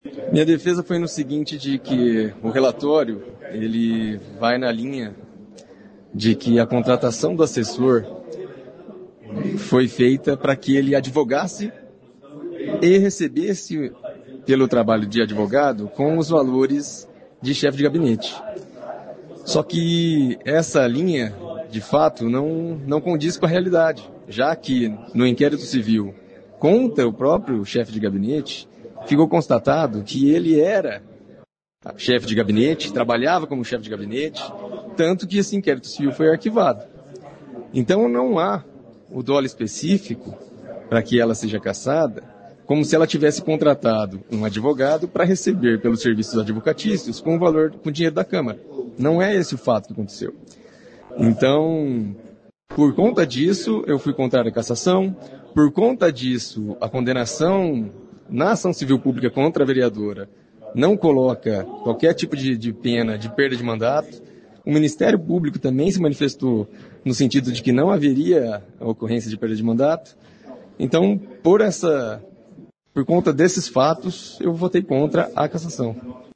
Vereador Daniel Malvezzi (Novo) defendeu a colega de partido no momento em que os vereadores puderam usar a palavra.